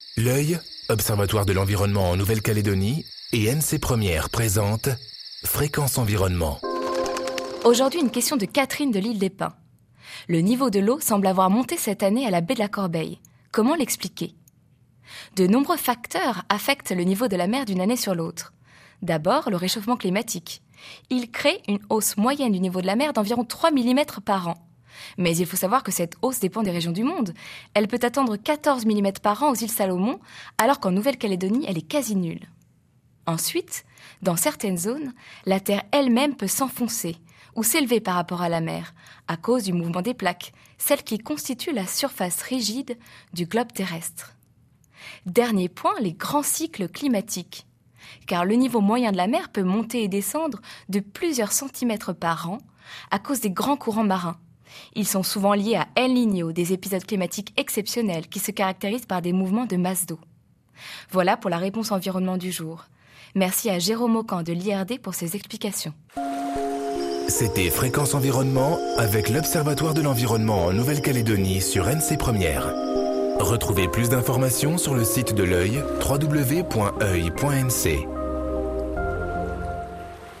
diffusée en décembre 2013 sur NC 1ère